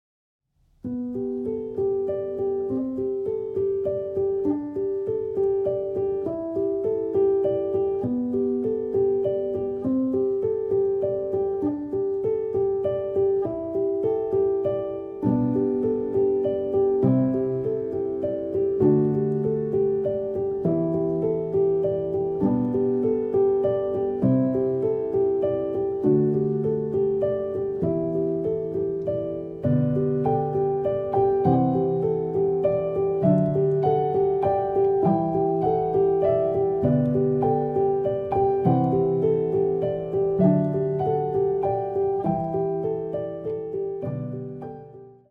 延々と続く桜並木を歩む夢を見るようなアルバムです。